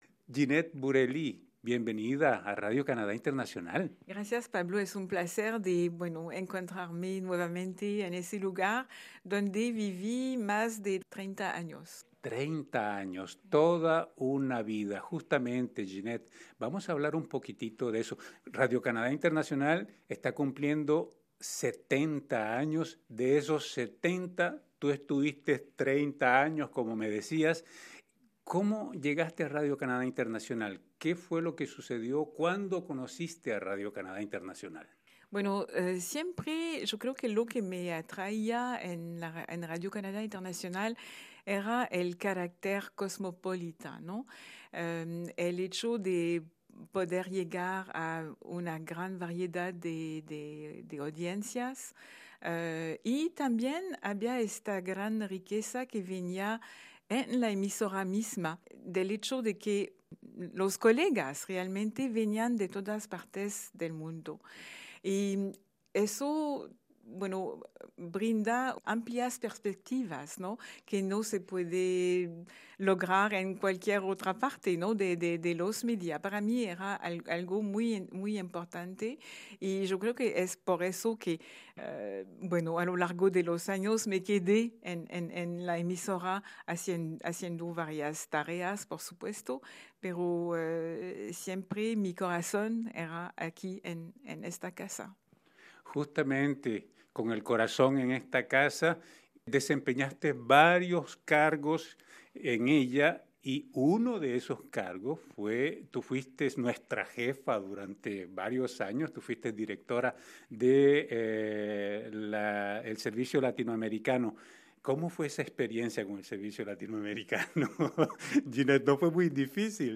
conversó con ella.